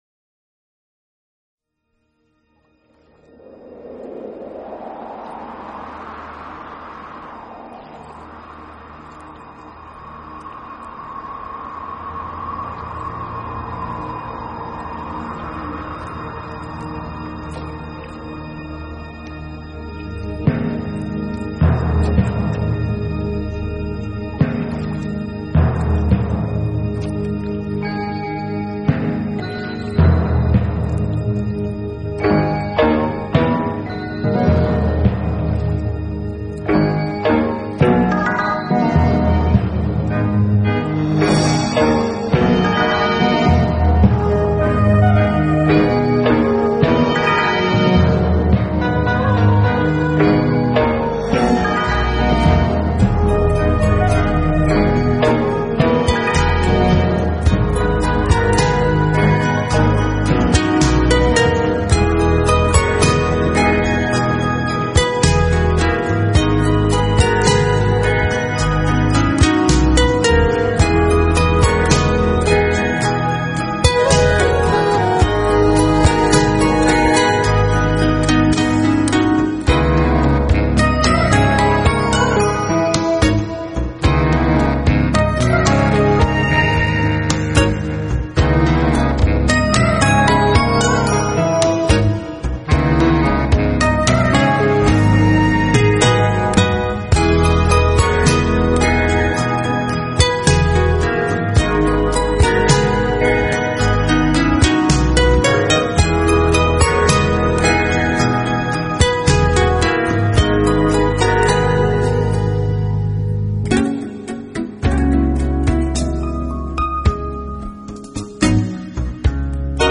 音乐类型：NewAge 新世纪
音乐风格：instrumental 器乐